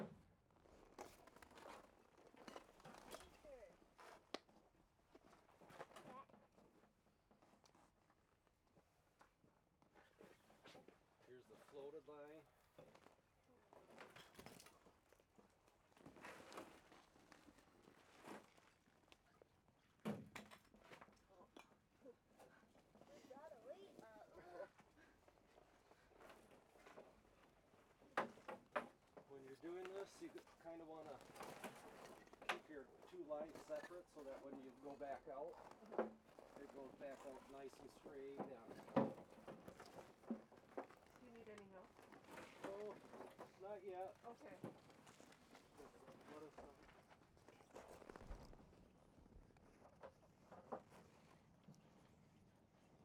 Index of /SkyCloud/Audio_Post_Production/Education/The Ways/2026-01-29 Ice Fishing Camp - Mole Lake/Day 3 - 1-31